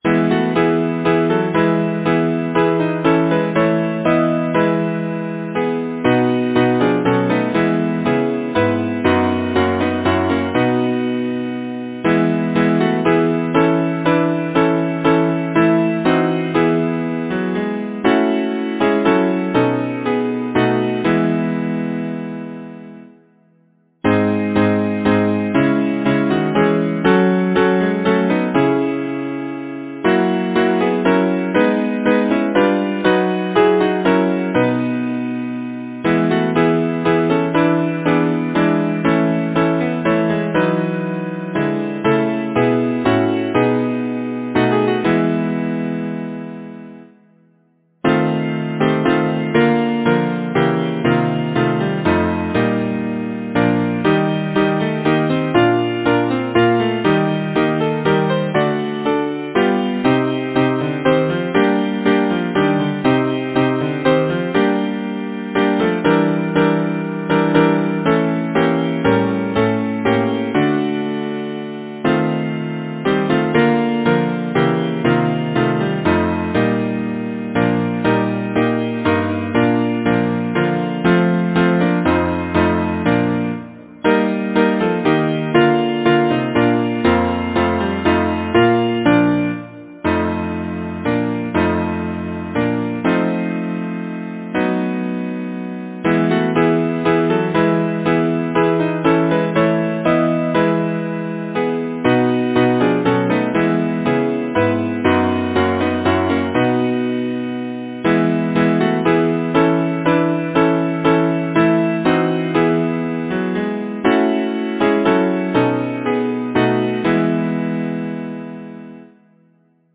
Title: Spring Song Composer: Hubbard W. Harris Lyricist: Number of voices: 4vv Voicing: SATB Genre: Secular, Partsong
Language: English Instruments: A cappella